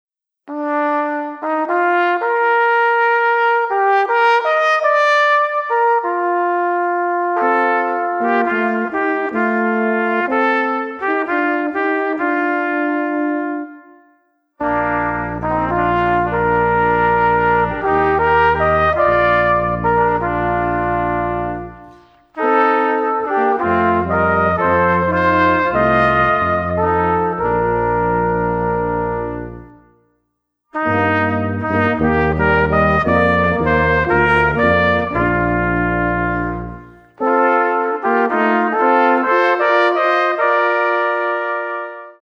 für Brass-Quartett